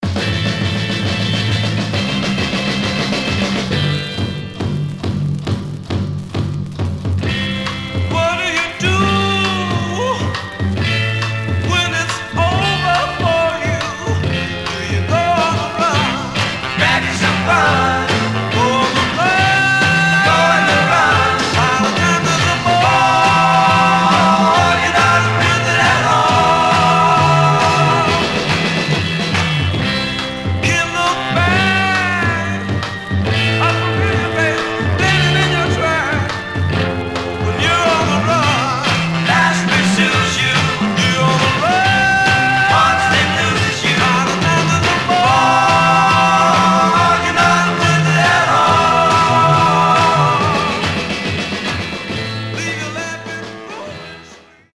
Mono
Garage, 60's Punk